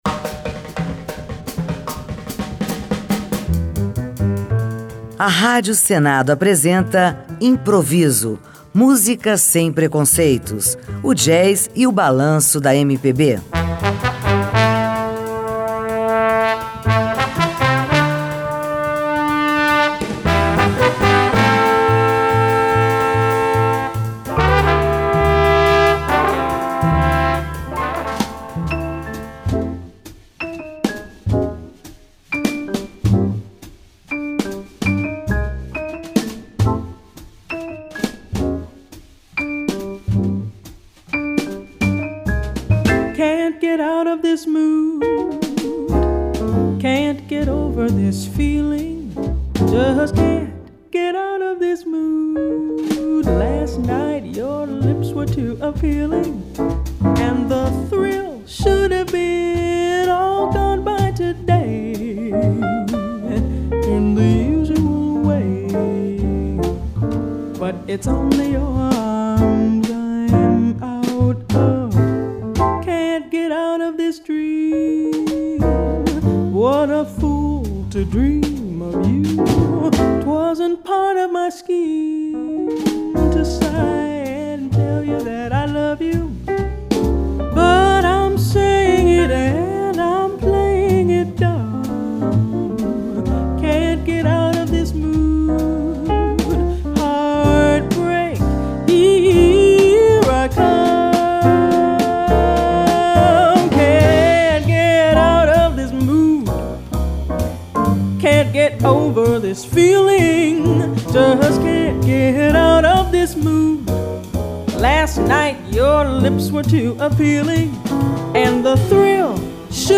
Suas interpretações encantam pela simplicidade e precisão.